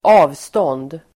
Uttal: [²'a:vstån:d]